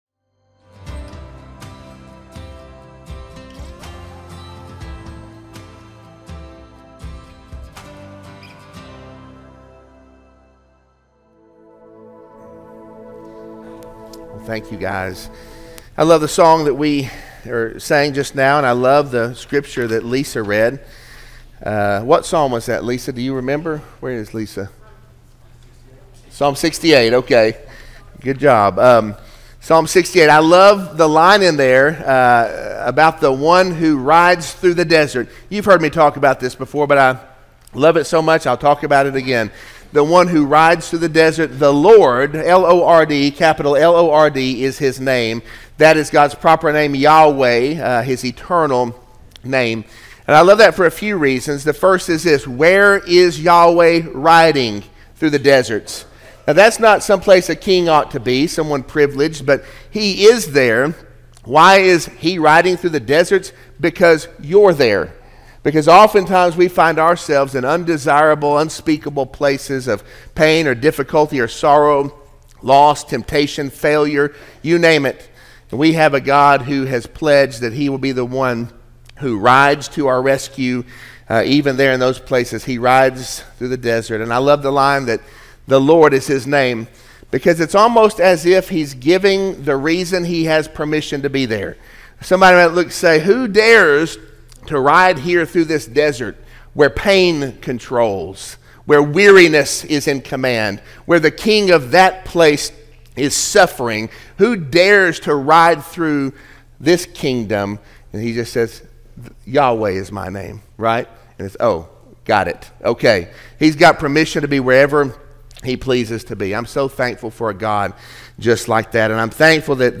Sermon-1-19-25-audio-from-video.mp3